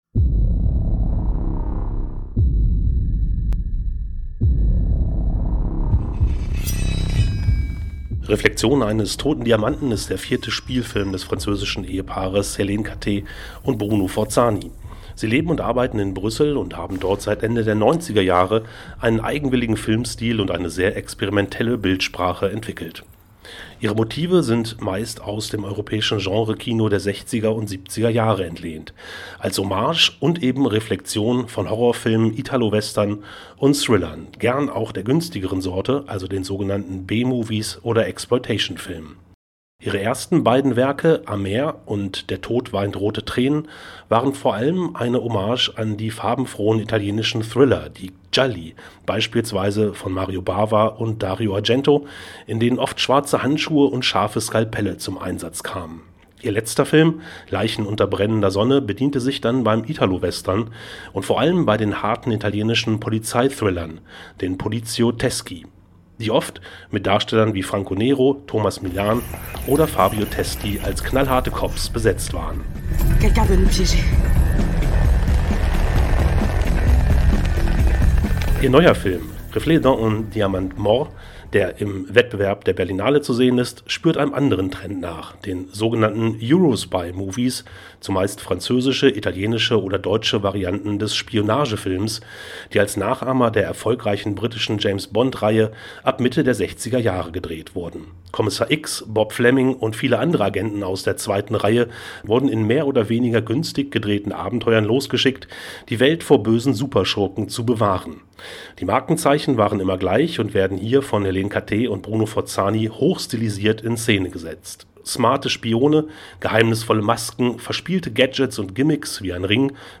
(Radiobeitrag zu „Reflet dans un diamant mort“)